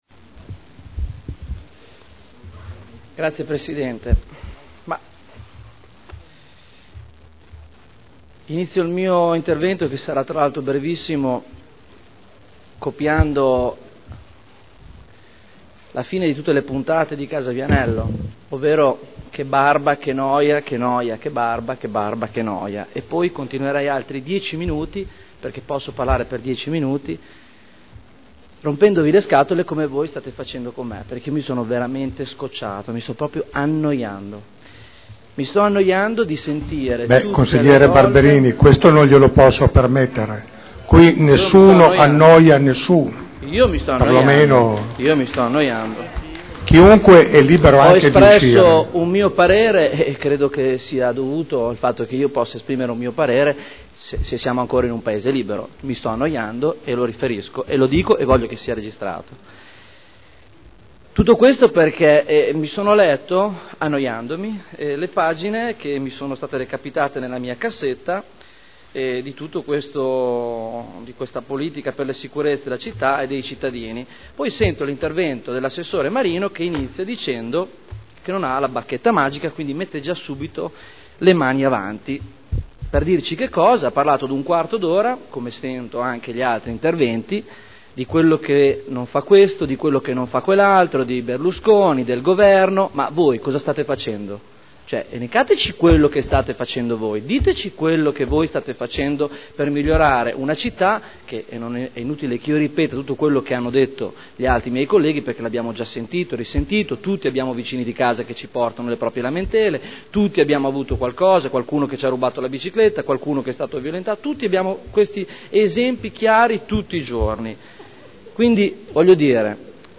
Stefano Barberini — Sito Audio Consiglio Comunale